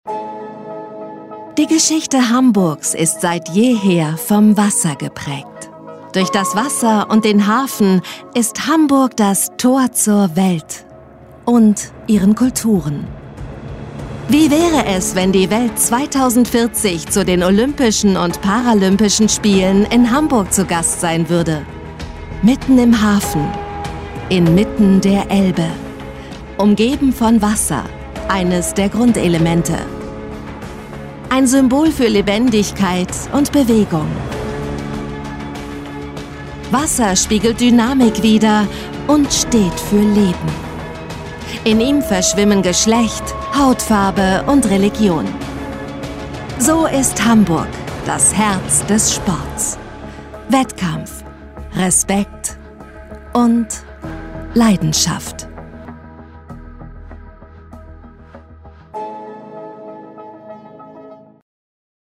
Natürlich, Cool, Vielseitig, Warm
Unternehmensvideo
The sound of her voice is middle-aged (about 30-50 years), warm, dynamic and changeable.